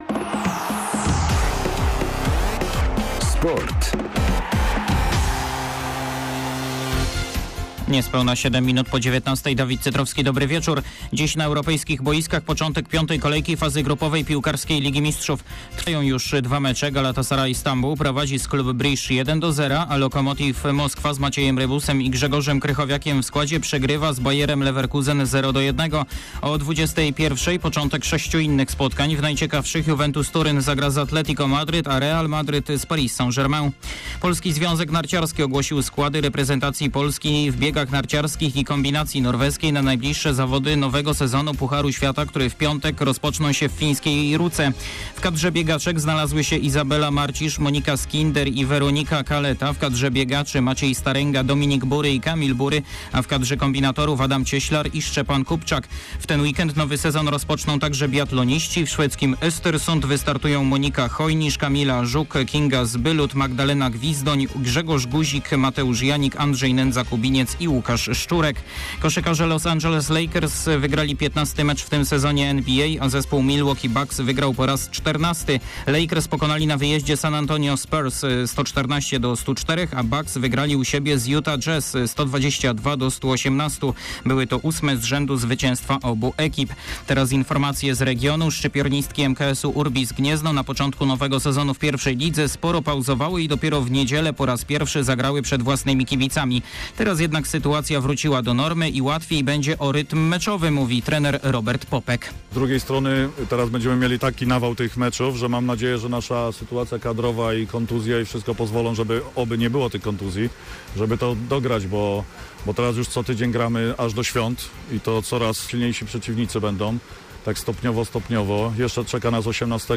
26.11. SERWIS SPORTOWY GODZ. 19:05